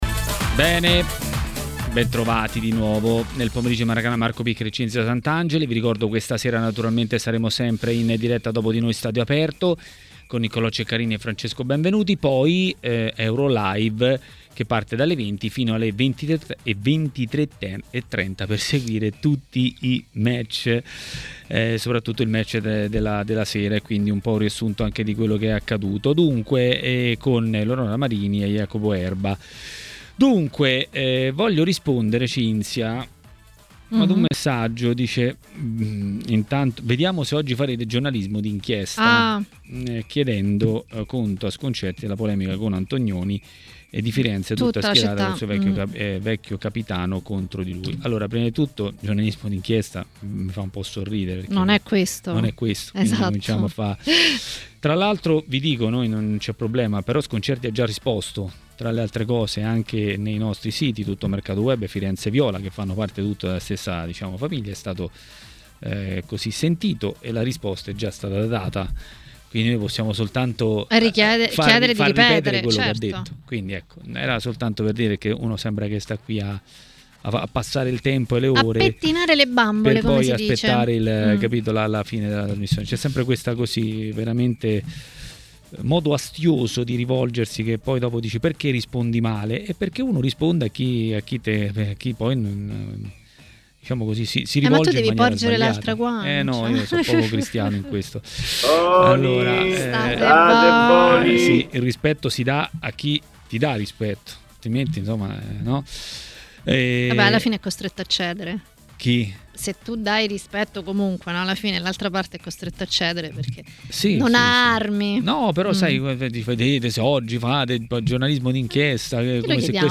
Ascolta l'audio A parlare di Euro 2020 a Maracanà, nel pomeriggio di TMW Radio, è stato il direttore Mario Sconcerti.